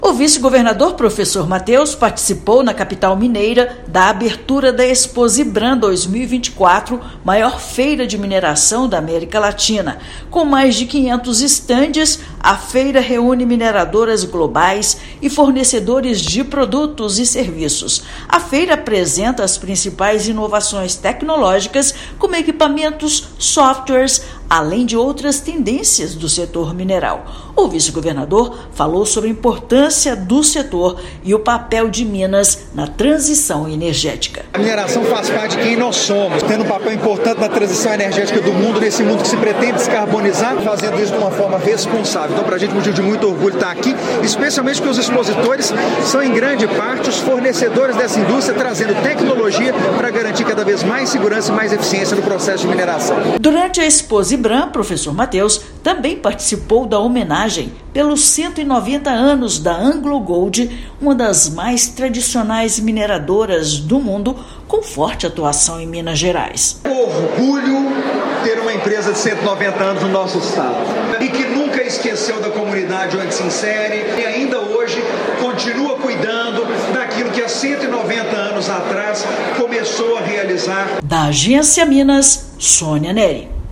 Realizada em Belo Horizonte, feira deve receber 70 mil pessoas em quatro dias, apresentando inovações em produtos, serviços e segurança. Ouça matéria de rádio.